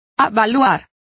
Complete with pronunciation of the translation of assess.